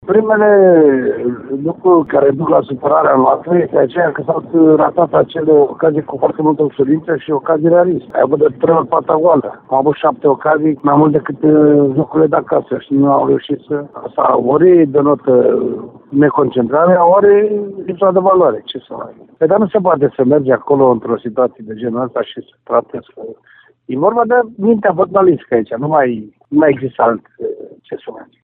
Antrenorul Ionuț Popa a vorbit pentru Radio Timișoara despre ocaziile rarissime ratate de alb-violeți în această partidă: